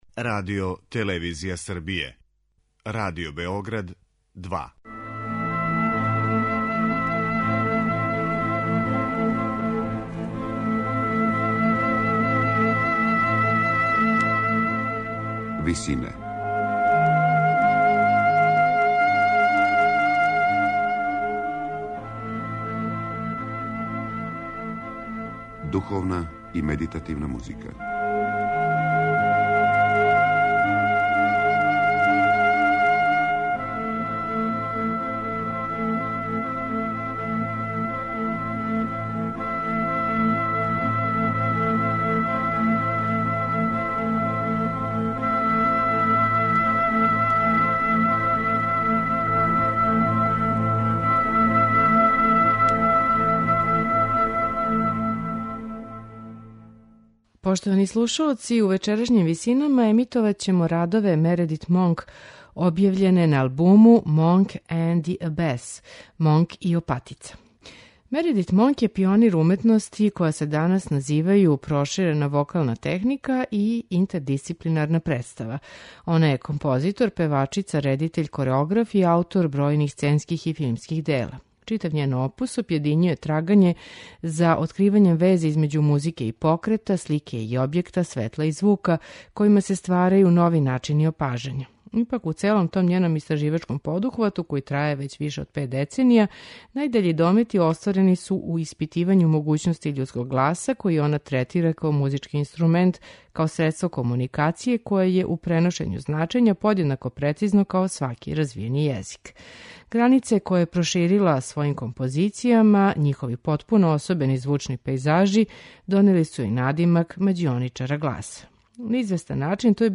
за вокални ансамбл